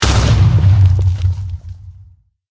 explode1.ogg